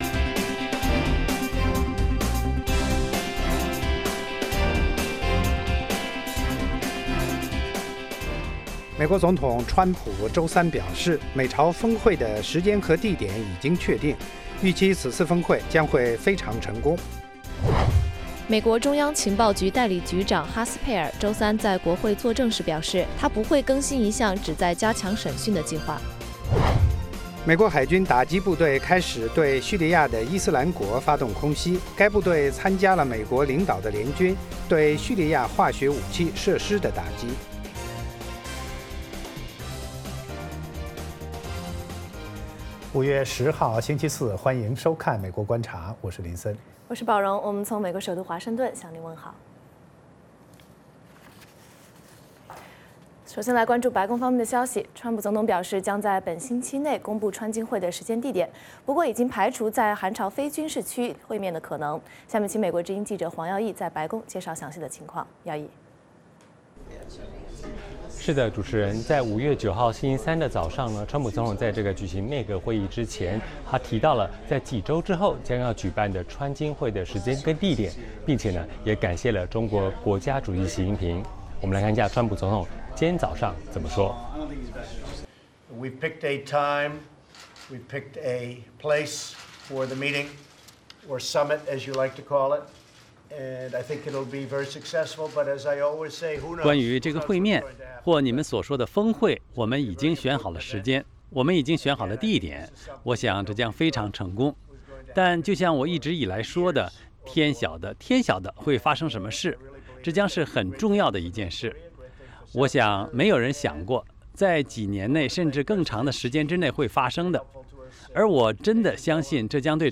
美国之音中文广播于北京时间晚上8－9点重播《VOA卫视》节目(电视、广播同步播出)。
“VOA卫视 美国观察”掌握美国最重要的消息，深入解读美国选举，政治，经济，外交，人文，美中关系等全方位话题。节目邀请重量级嘉宾参与讨论。